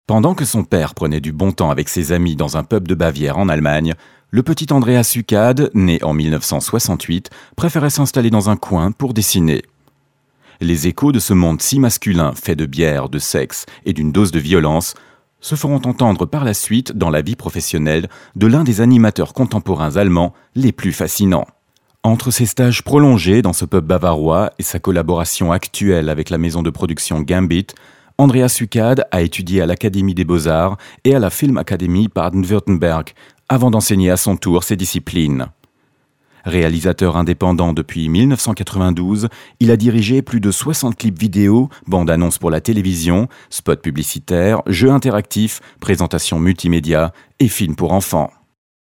Dank seiner angenehmen Tonlage, nicht zu tief und auch nicht zu hell, ist er sehr flexibel einsetzbar.